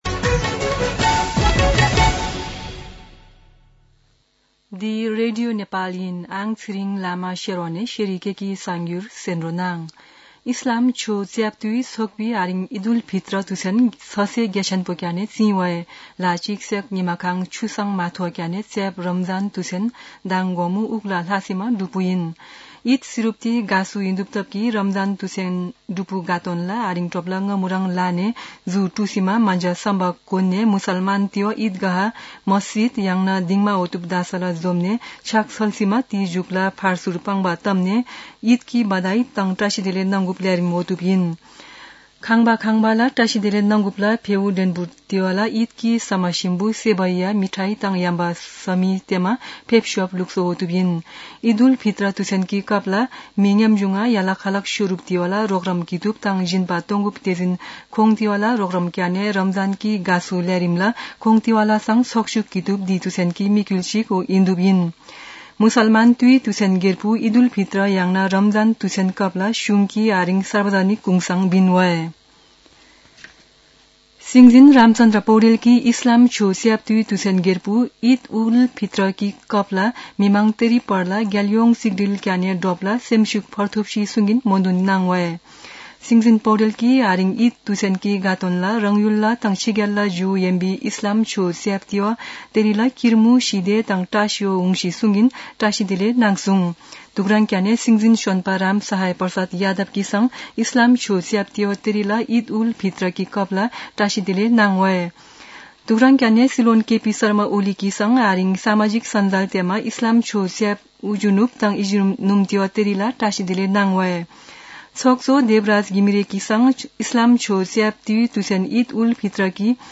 शेर्पा भाषाको समाचार : १८ चैत , २०८१
Sherpa-News-2.mp3